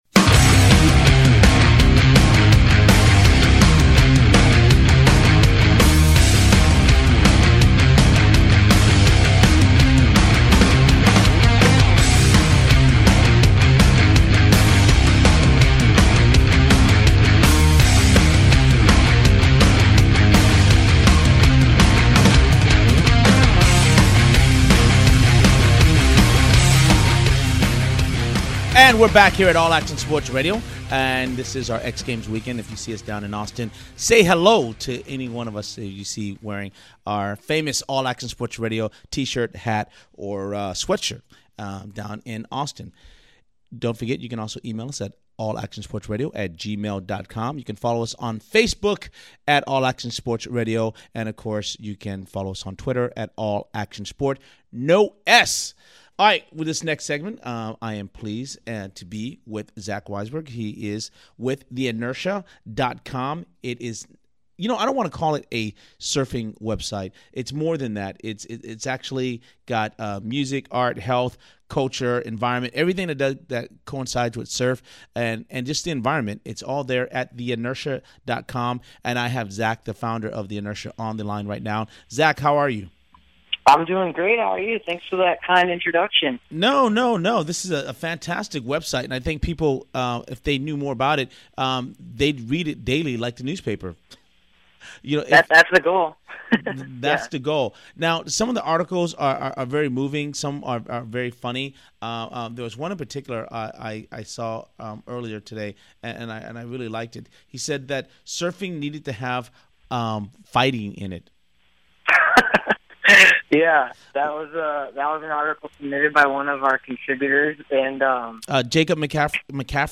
AASR Interview